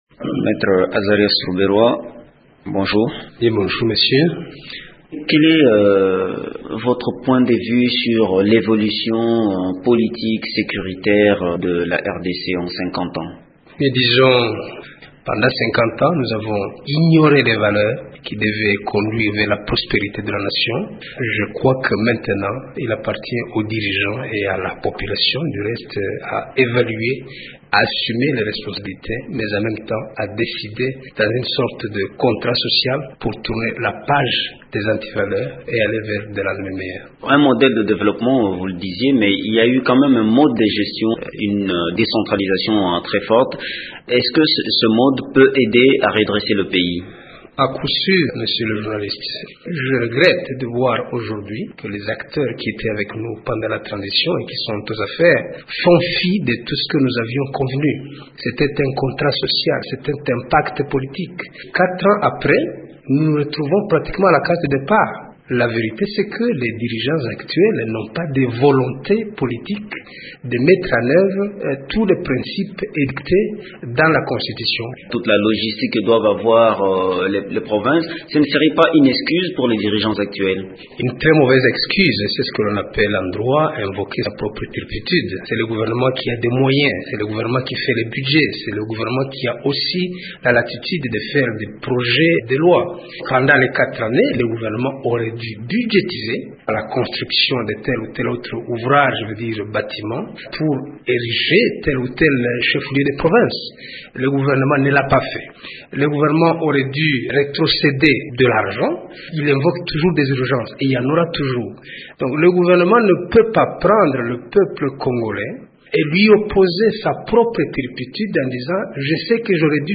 Il évalue la situation sécuritaire de la RDC 50 ans après son indépendance. Le processus de décentralisation se trouve aussi au cœur de cet entretien.